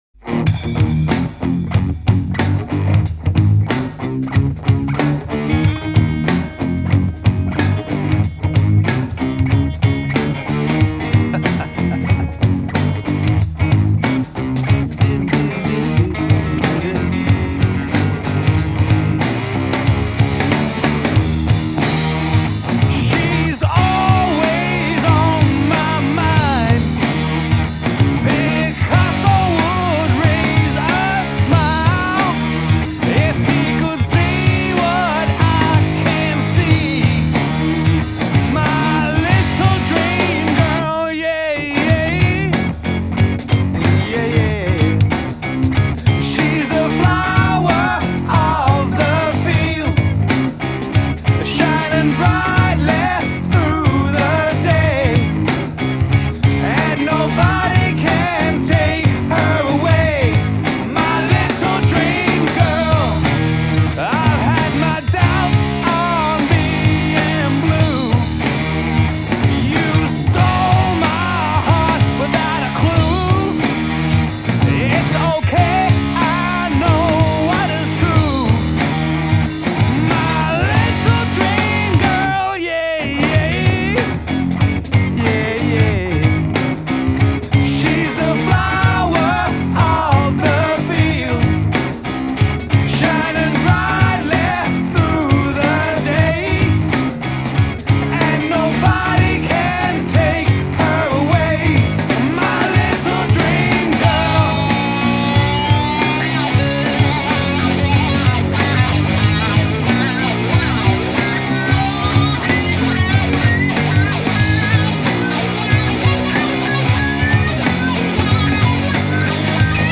singer and guitarist
Drums
Bass
Keyboards